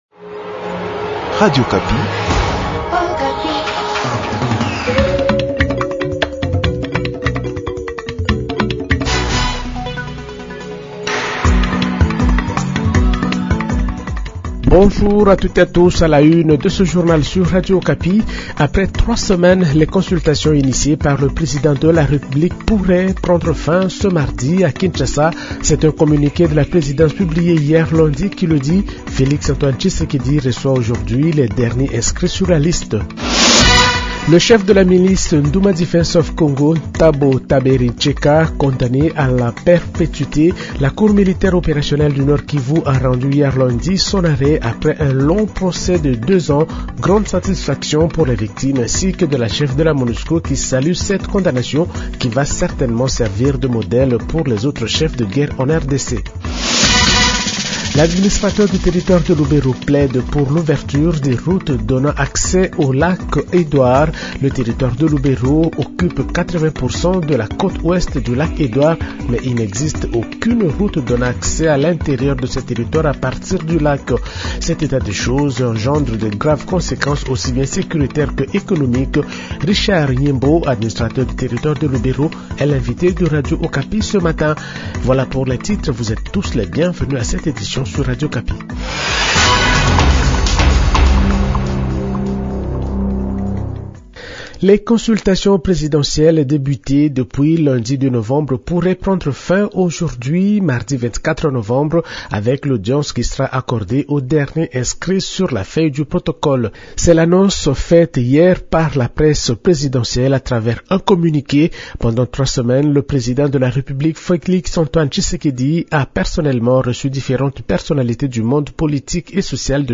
Journal Francais matin 6h00